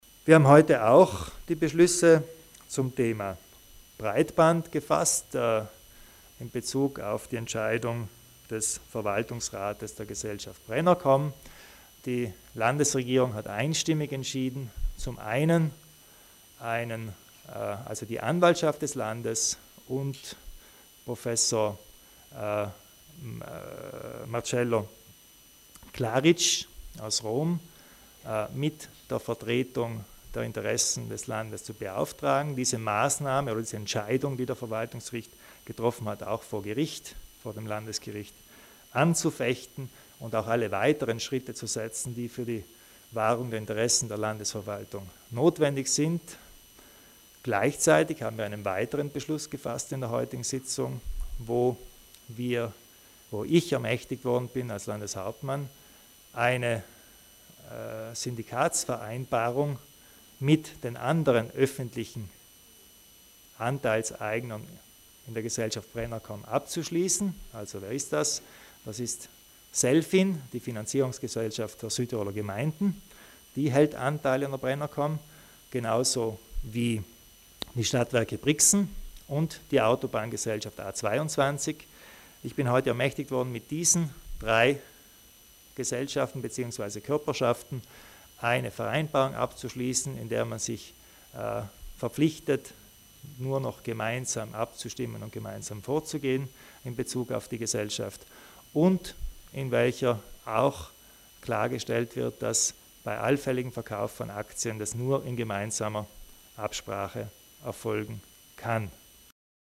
Bei der Pressekonferenz im Anschluss an die Sitzung der Landesregierung stellte Landeshauptmann Kompatscher klar, dass es im Sinne der Bürger sei, dass die Breitbandinfrastruktur in öffentlicher Hand bleibe.